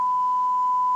Censored Beep